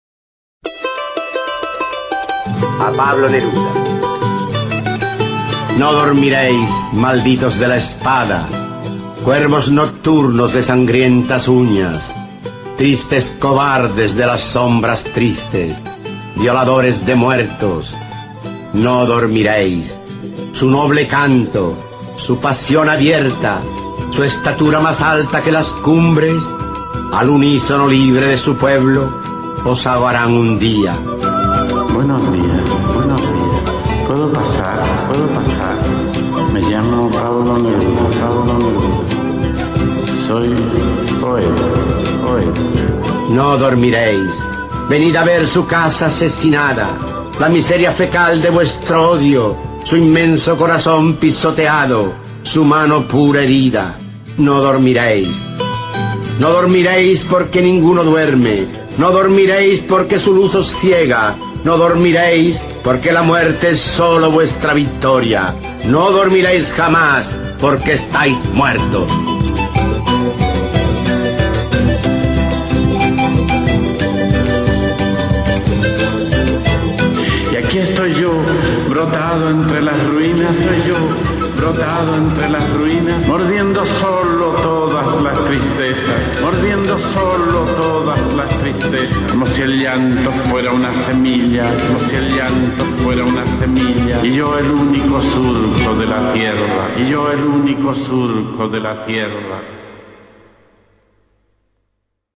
Archivo de sonido con la voz del escritor español Rafael Alberti, quien recita su poema “A Pablo Neruda, con Chile en el corazón" (Fustigada luz, 1972-1978).
Se recomienda este recurso para promover un encuentro placentero de los estudiantes con el texto literario, recitado en la voz de su autor.